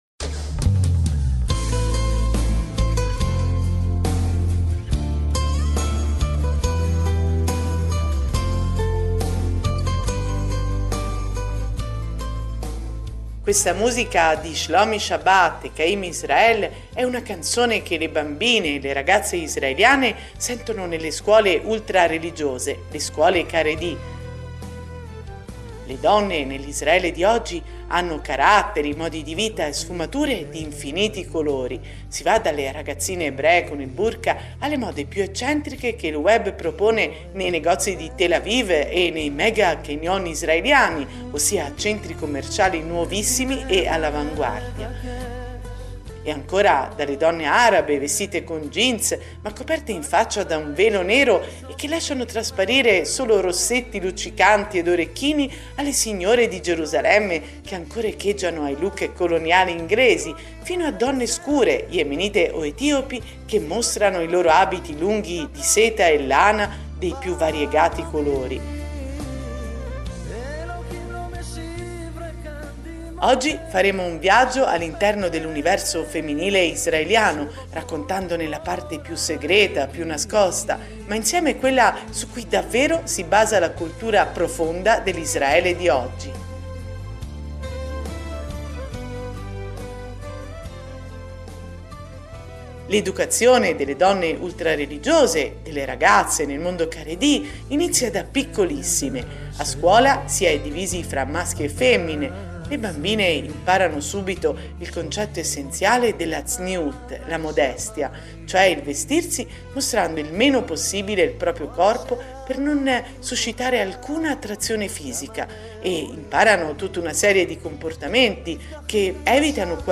Il programma, che si avvale di preziose interviste e documenti sonori, ci racconta gli usi e la vita delle donne e ragazze Haredi (cioè seguaci delle più rigide norme religiose ebraiche) che indossano una sorta di burka che copre interamente il viso, non usano internet e tv e si sposano prestissimo dopo aver incontrato solo due o tre volte il futuro marito.